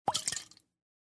Звуки аэрозоля
Аэрозольный баллончик оказался в воде